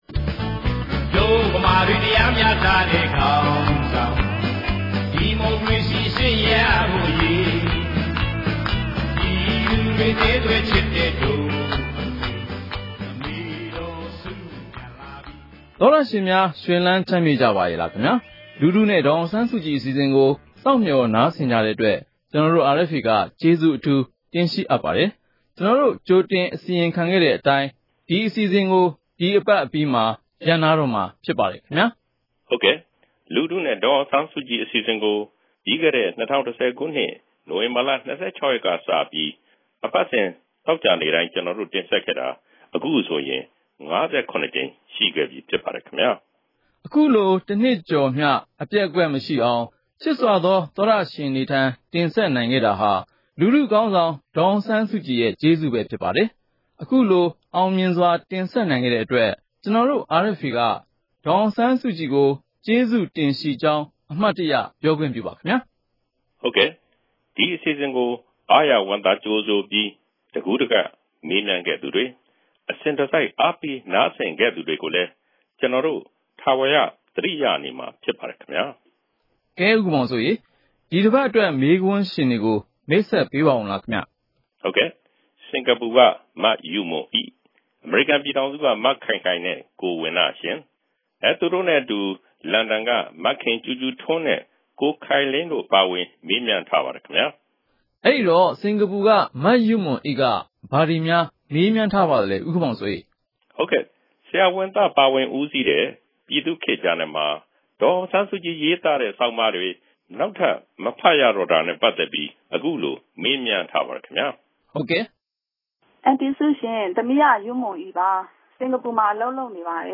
လူထုနဲ့ ဒေါ်အောင်ဆန်းစုကြည် အစီအစဉ်ကို RFA က အပတ်စဉ် သောကြာနေ့ ညတိုင်းနဲ့ ဗုဒ္ဓဟူးနေ့ မနက်တိုင်း တင်ဆက်နေပါပြီ။ ဒီ အစီအစဉ်ကနေ ပြည်သူတွေ သိချင်တဲ့ မေးခွန်းတွေကို ဒေါ်အောင်ဆန်းစုကြည် ကိုယ်တိုင် ဖြေကြားပေးမှာ ဖြစ်ပါတယ်။
အဲဒီ တယ်လီဖုန်း နံပါတ်ကို RFA က ဆက်သွယ်ပြီး ကာယကံရှင်ရဲ့ မေးမြန်းစကားတွေကို အသံဖမ်းယူကာ ဒေါ်အောင်ဆန်းစုကြည်ရဲ့ ဖြေကြားချက်နဲ့အတူ ထုတ်လွှင့်ပေးမှာ ဖြစ်ပါတယ်။